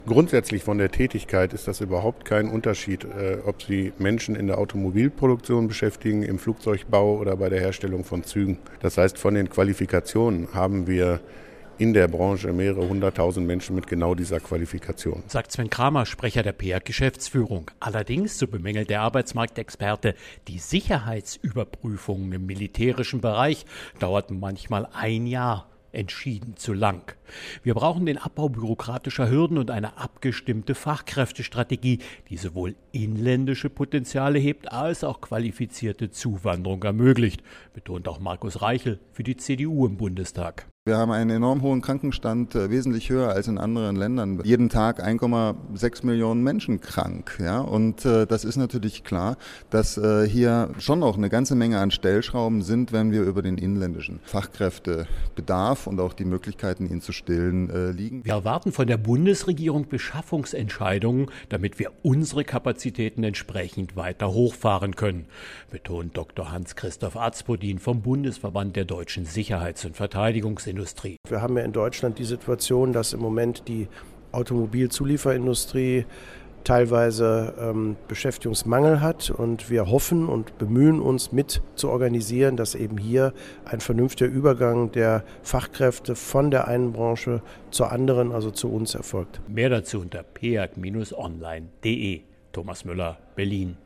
Für die Personaldebatten produzieren wir jeweils Presseinfos, O-Töne und einen sendefertigen Radiobeitrag zum kostenfreien Download.